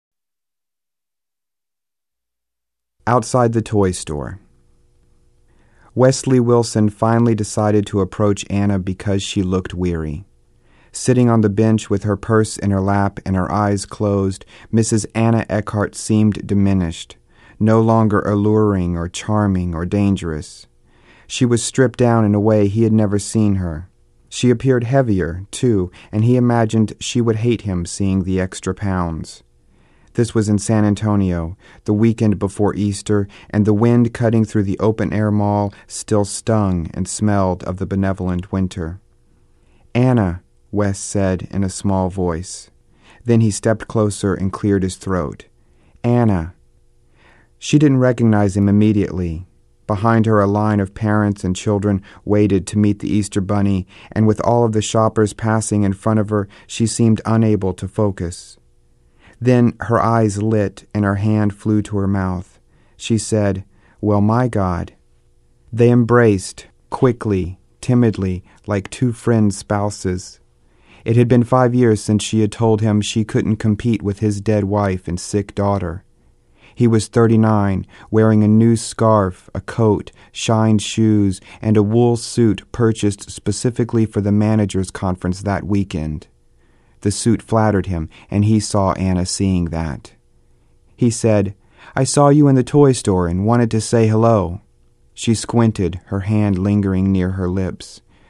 and originally aired on WSUI, the NPR affiliate in Iowa City, IA.